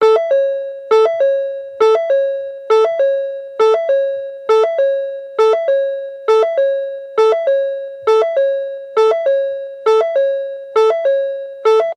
Звуки дверей автомобиля
Звук сигнала незакрытой двери автомобиля